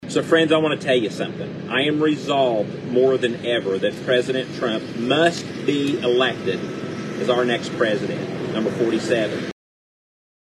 Former Republican candidate for Illinois governor Darren Bailey is standing behind former President Donald Trump despite his legal woes. In a Facebook live video, the former state Senator said Trump must be elected as our next president.